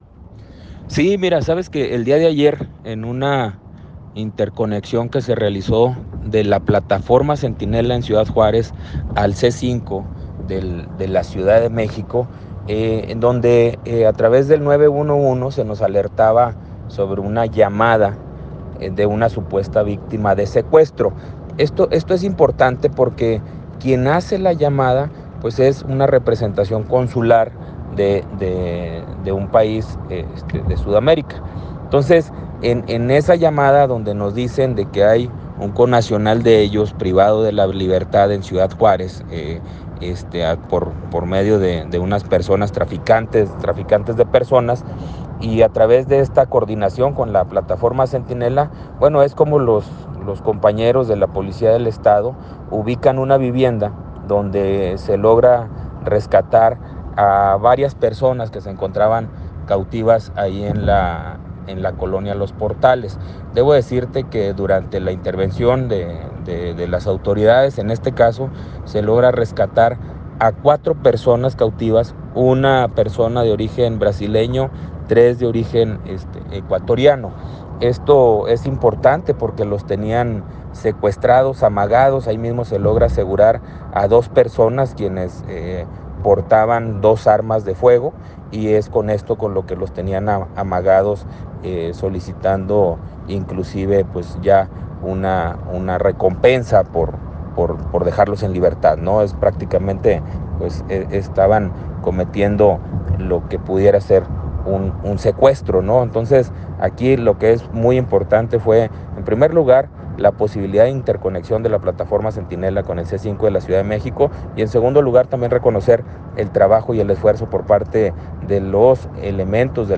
Gilberto Loya – Secretario de Seguridad Pública Estatal